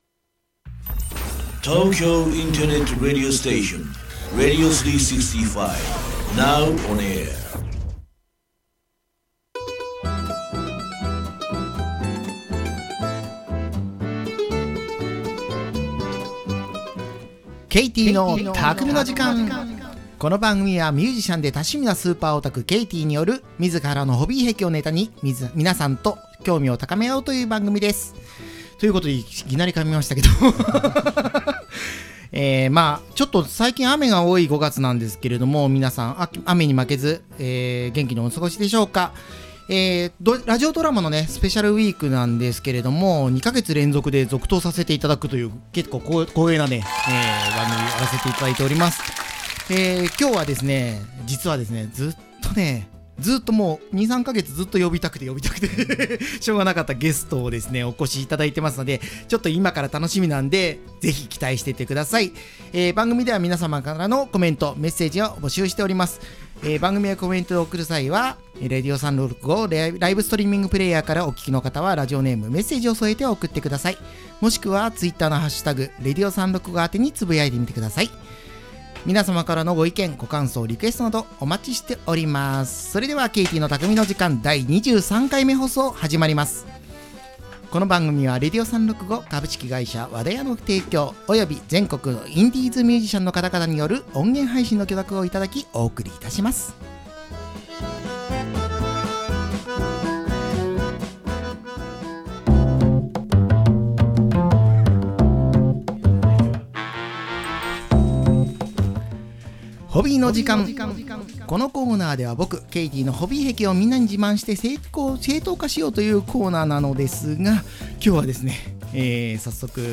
【この音源は生放送のアーカイブ音源となります】